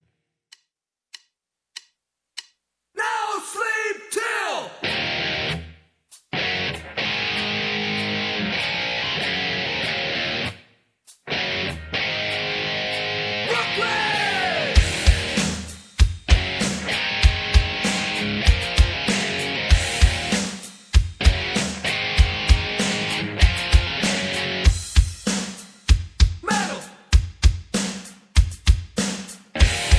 karaoke, mp3 backing tracks
rock, hip hop, rap